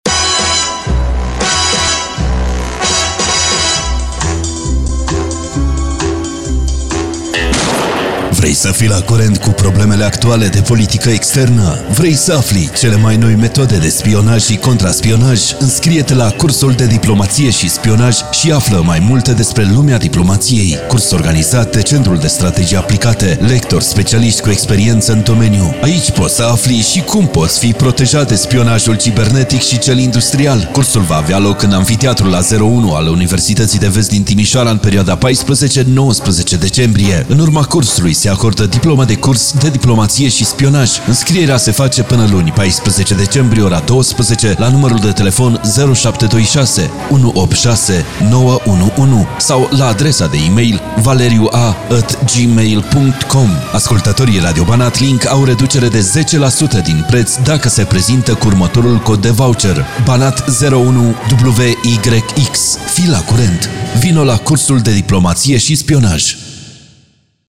Promo-Curs-Diplomatie.mp3